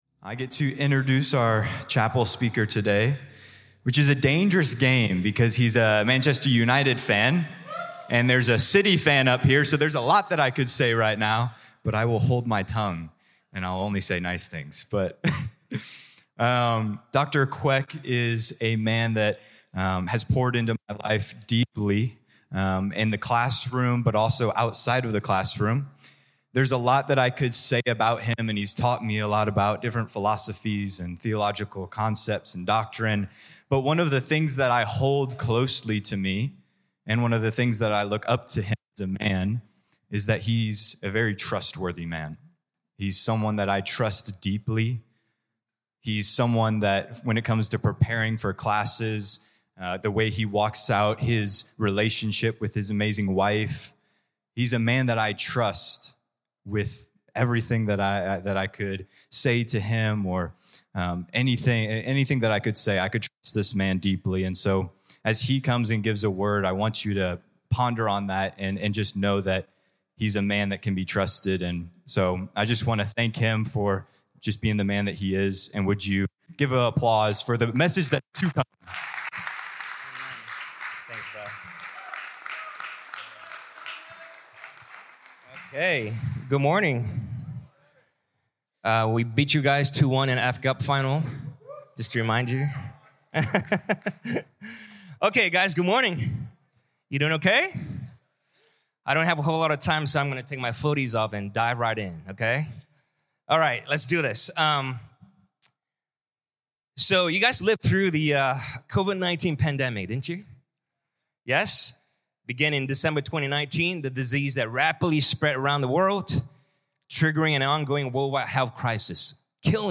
This talk was given in chapel on Wednesday, November 13th, 2024 God Bless you.